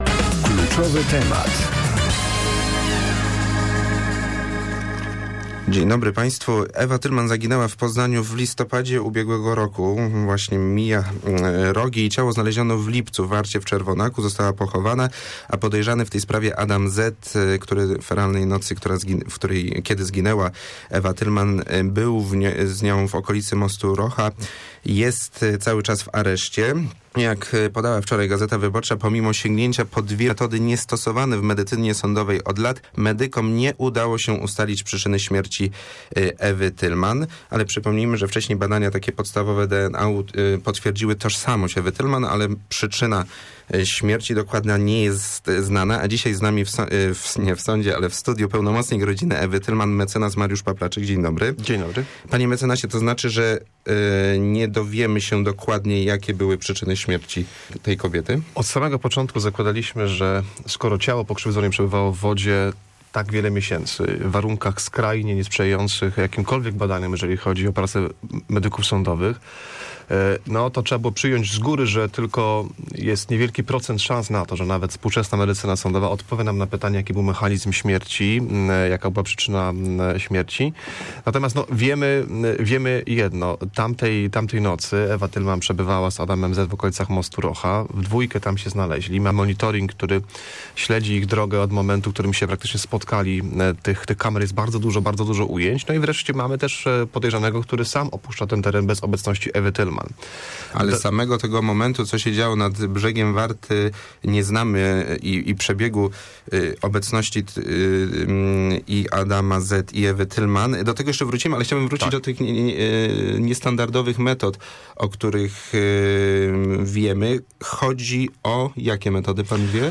ye9kg11w1iyldx4_rozmowa_-dla_internetu.mp3